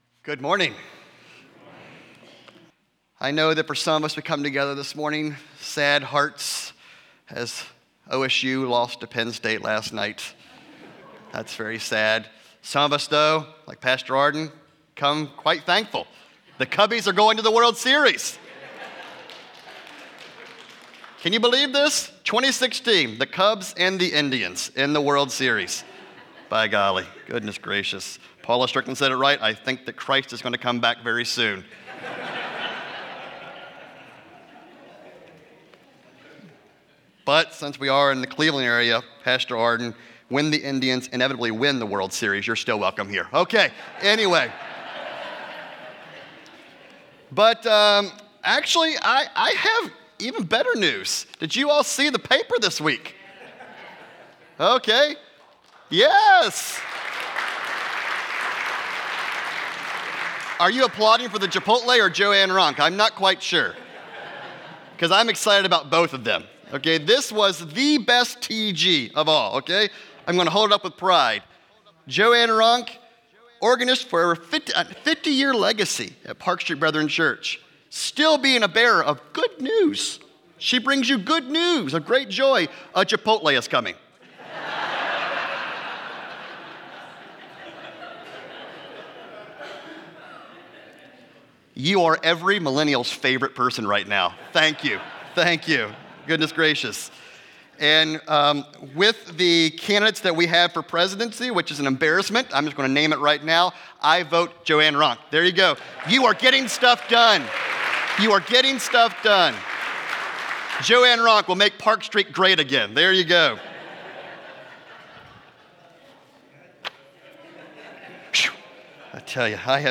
Sermons - Park Street Brethren Church